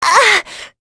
Talisha-Vox_Damage_04.wav